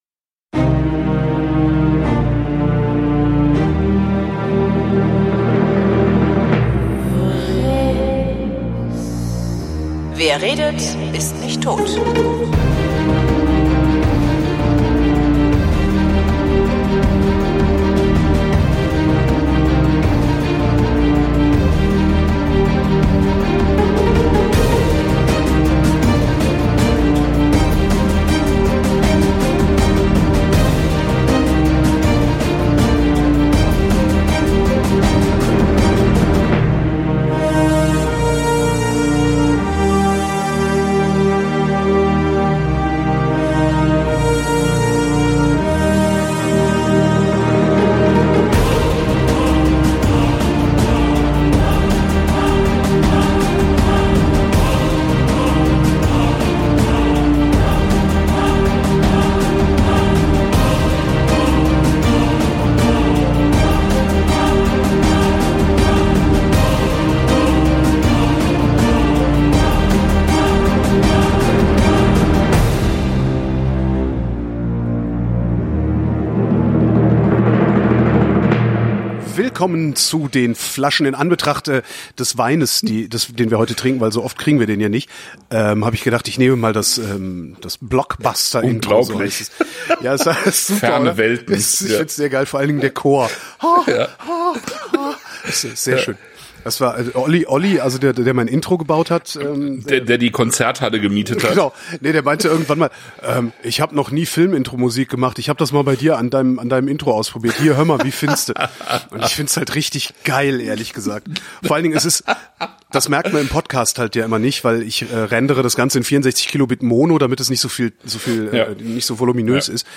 Wir sitzen zusammen, trinken und reden. Es gibt Porto Dry White, Porto Ruby, Porto Tawny und Porto LBV 2013 – alles von Niepoort.